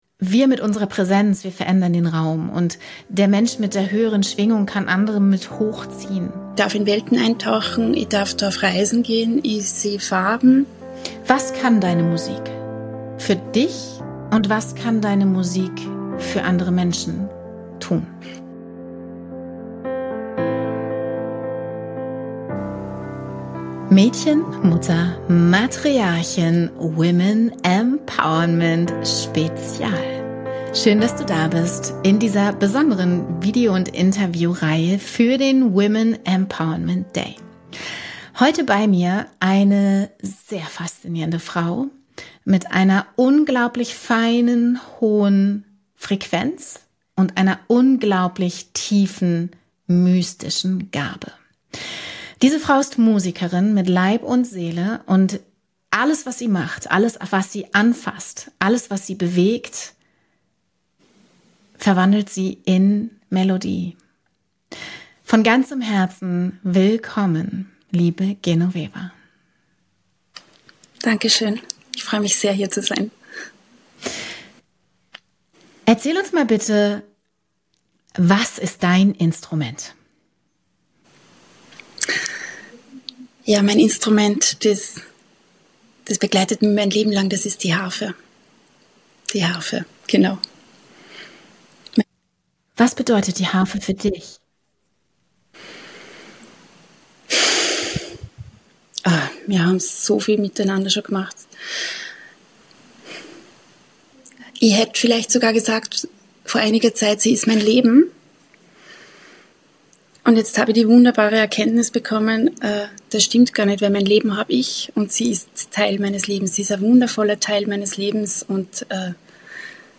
Wenn ein Instrument dich zurück in dein wahres Wesen führt? In diesem Interview begegnest du einer Frau, die Menschen in Musik übersetzt.